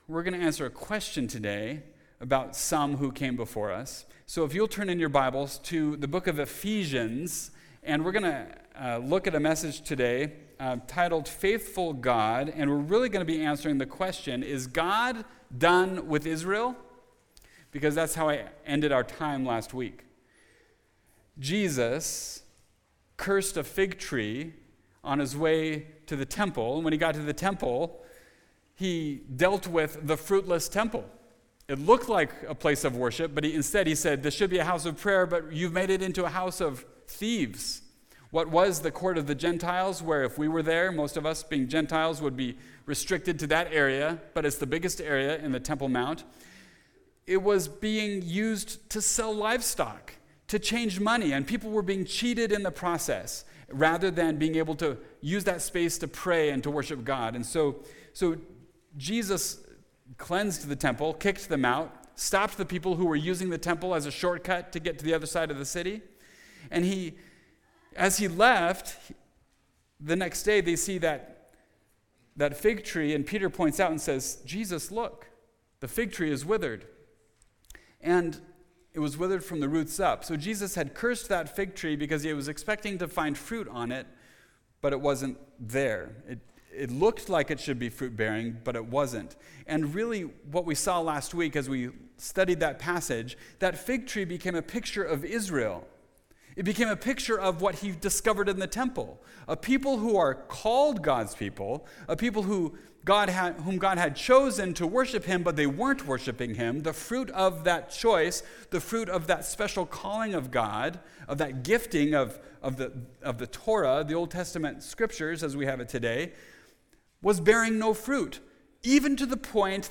Series: Topical Message